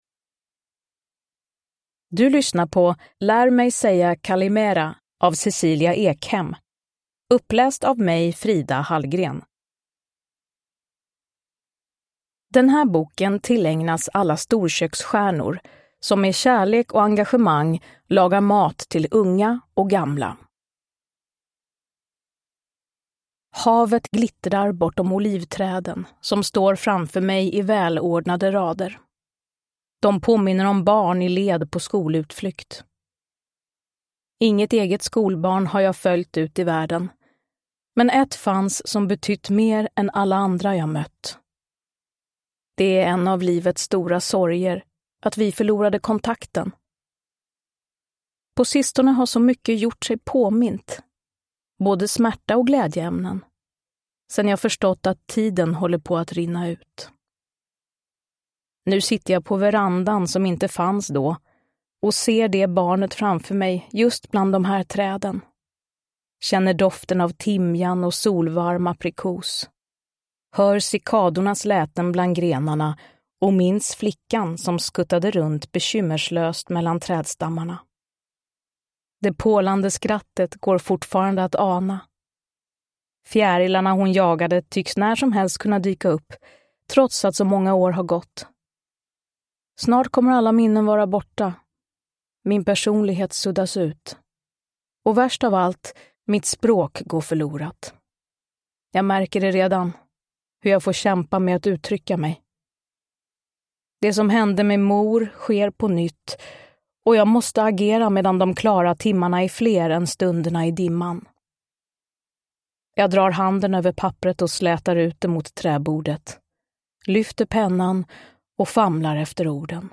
Uppläsare: Frida Hallgren
Ljudbok